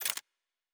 pgs/Assets/Audio/Sci-Fi Sounds/Weapons/Weapon 04 Foley 2.wav at 7452e70b8c5ad2f7daae623e1a952eb18c9caab4
Weapon 04 Foley 2.wav